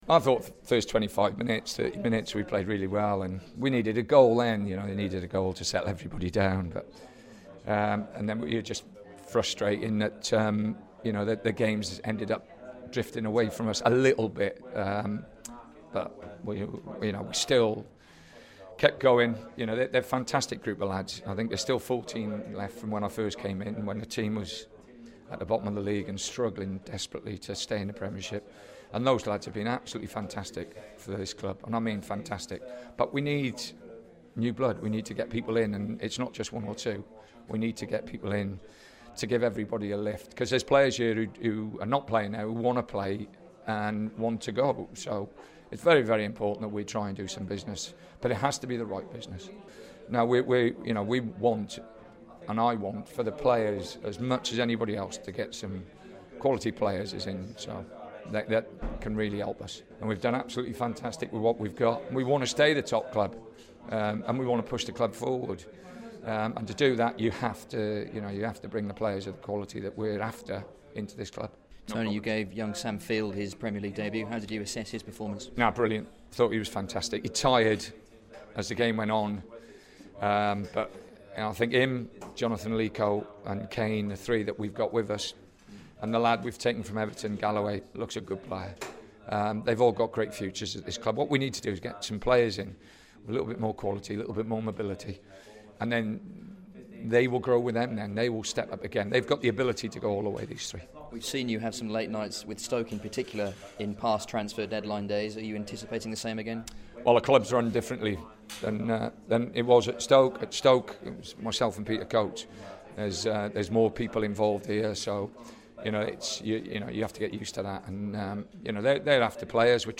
Baggies boss Tony Pulis speaks to BBC WM following the goalless draw with Boro - and a need for new players as transfer deadline day draws nearer